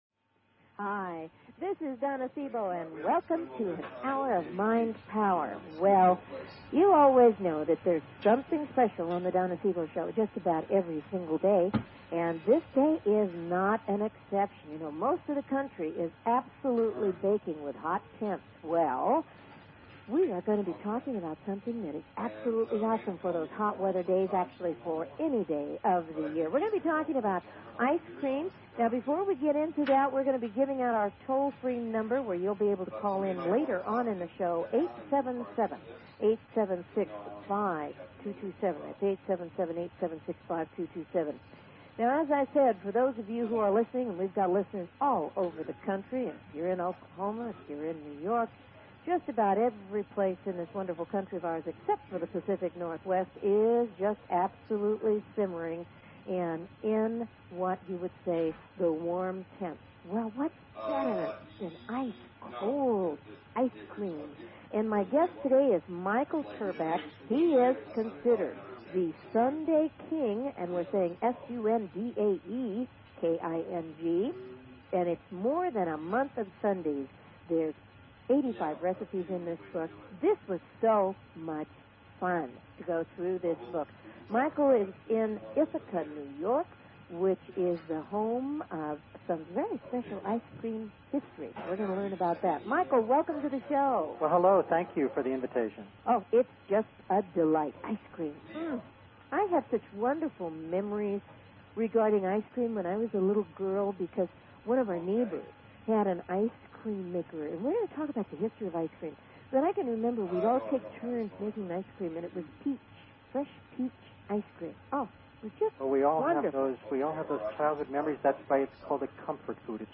This is a wonderful and absolutely delicious interview especially if you are a lover of ice cream and history. Join us for a fun time and wonderful ice cream recipes.